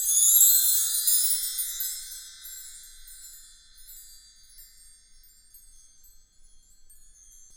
Index of /90_sSampleCDs/Roland LCDP03 Orchestral Perc/PRC_Wind Chimes1/PRC_W.Chime Up